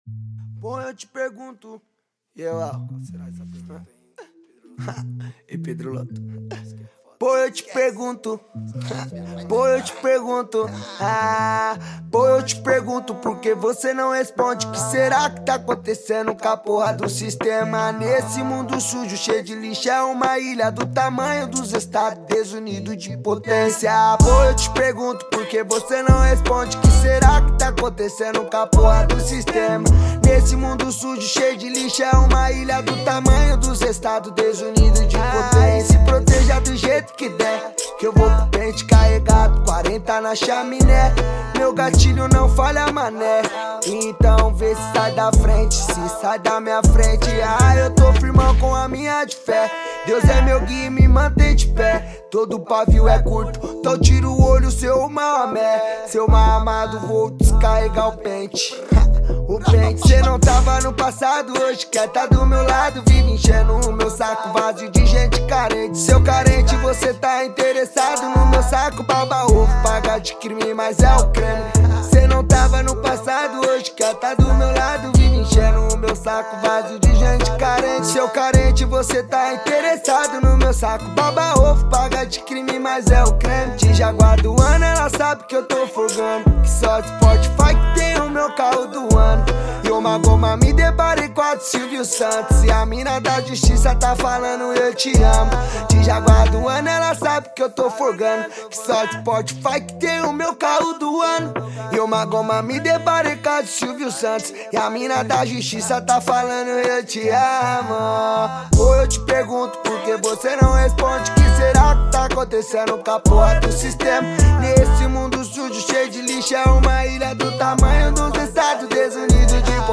2024-08-30 15:18:52 Gênero: MPB Views